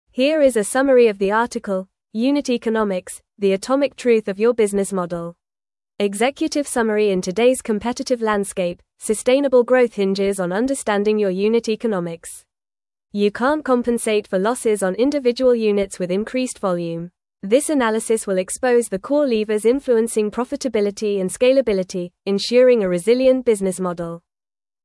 Strategic Briefing